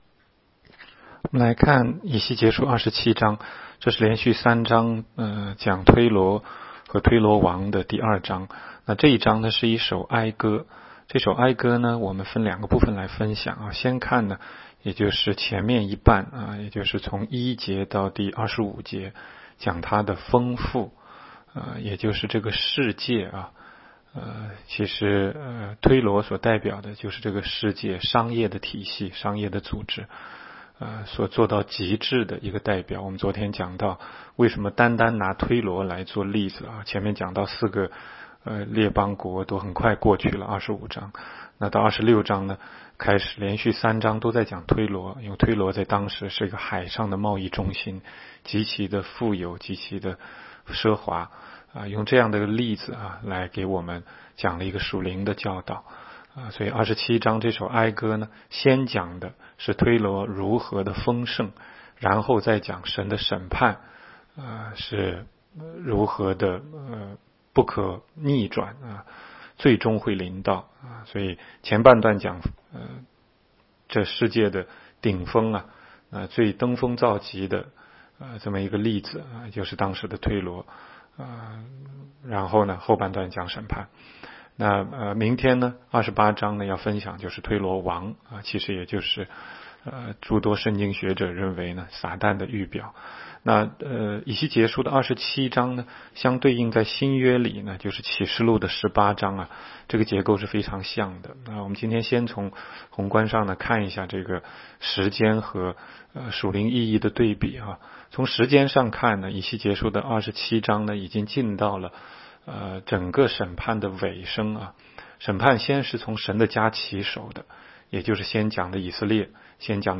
16街讲道录音 - 每日读经 -《以西结书》27章